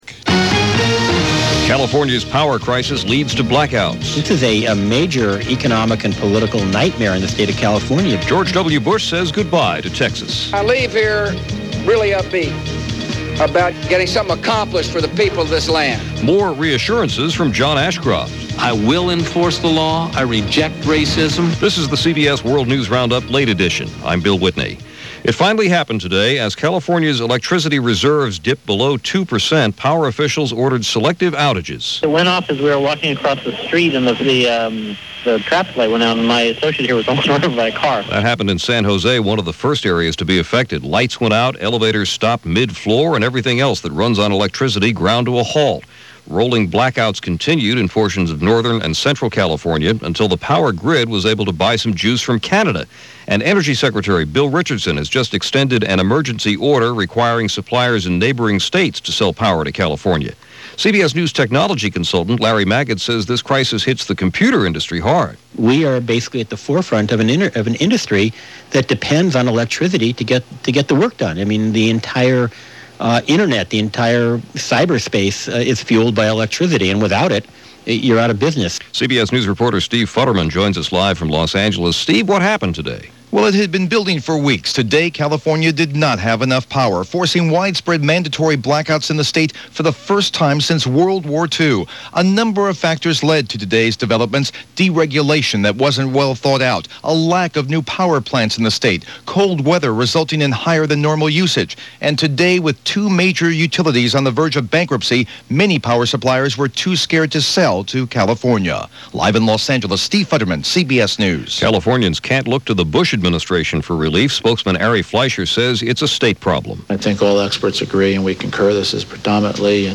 And while California was dealing with blackouts that’s just a little of what went on, this January 17, 2001 as presented by The CBS World News Roundup Late Edition.